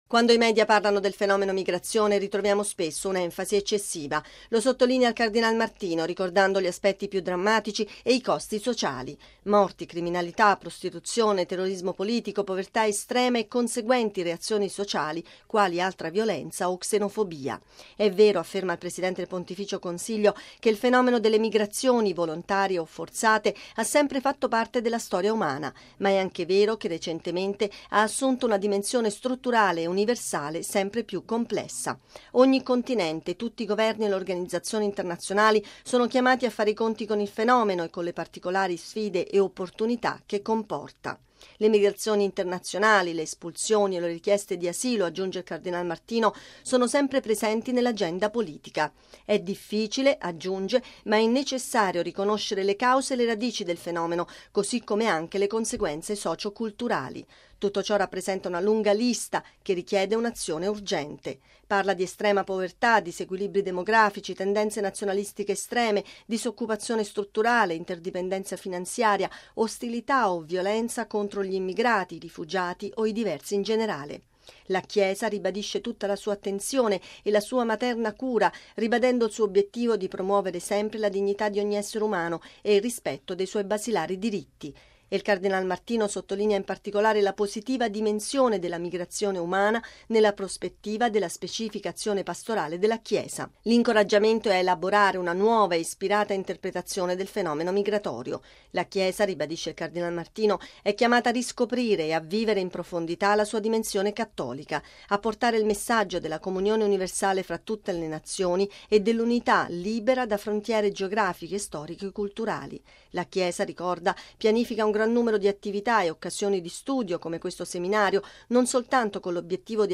I lavori si sono aperti oggi a Nairobi, in Kenya, e proseguiranno fino a giovedì. Il cardinale Raffaele Martino, presidente del dicastero, ha tenuto il discorso di apertura.